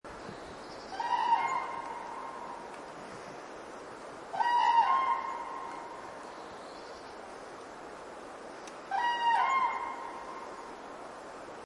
Kranich Ruf 2
Der Ruf des Kranichs ist sehr markant und lässt sich als trompetenähnlich beschreiben. Er klingt tief und laut, fast wie ein „krrruu“.
Kranich-Ruf-Voegel-in-Europa-2.mp3